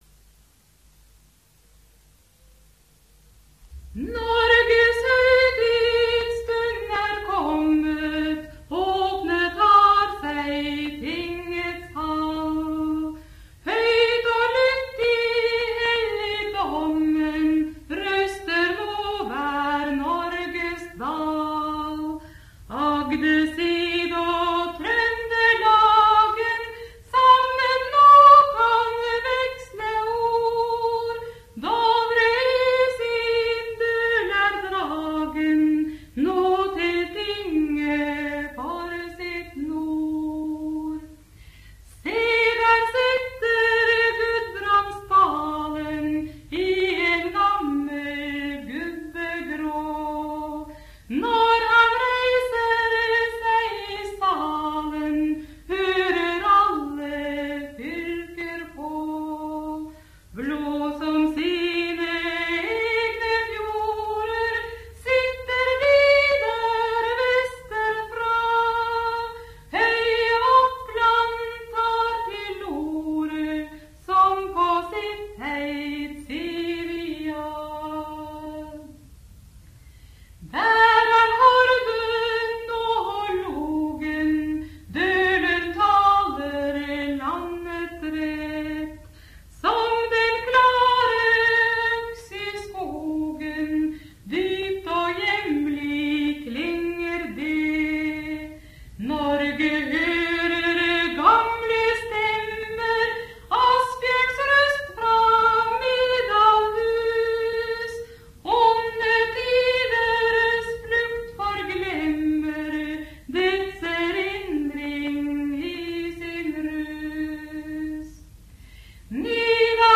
Folketone frå Telemark